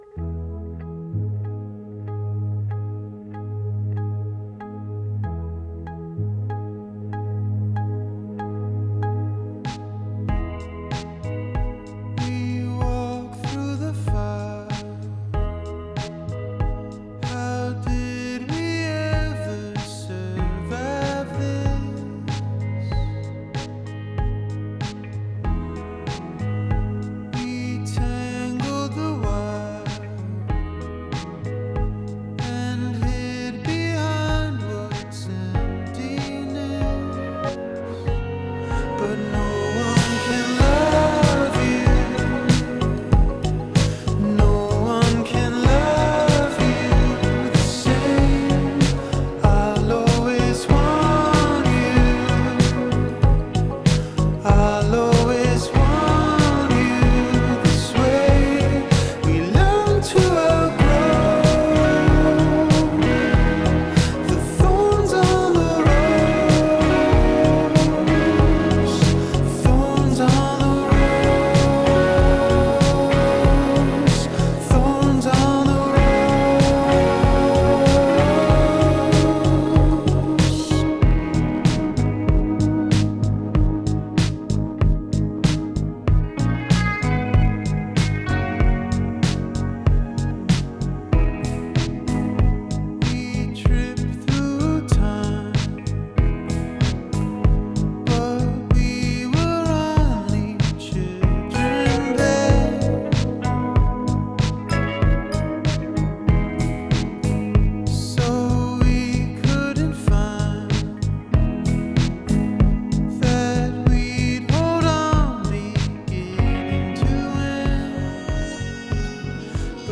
Resynator / I Speak Machine Synth Demo + Interview — Live broadcast from Synthplex